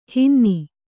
♪ hinni